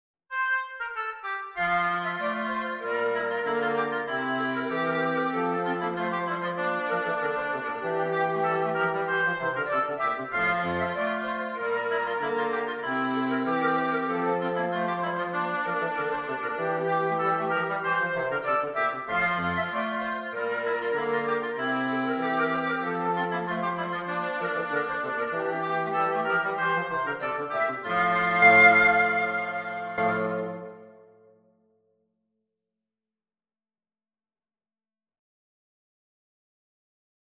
Free Sheet music for Choir (SATB)
Classical (View more Classical Choir Music)
(MIDI)